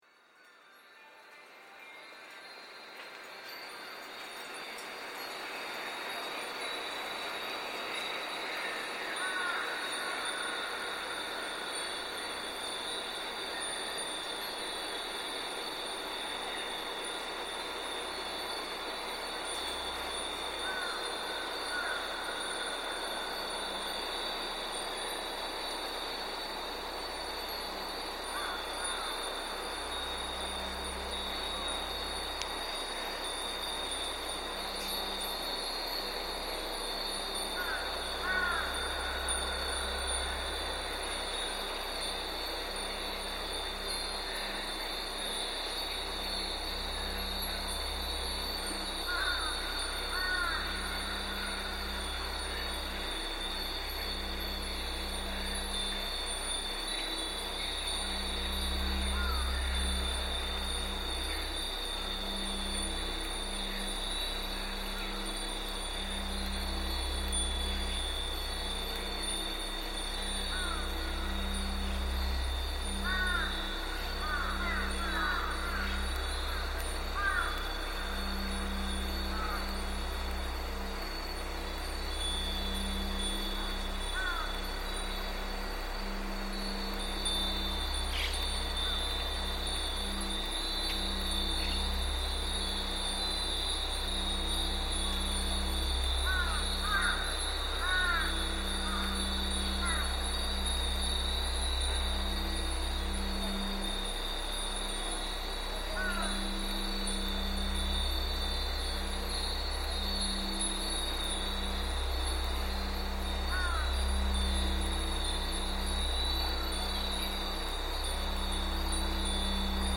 Wind bells in Ohta Ward reimagined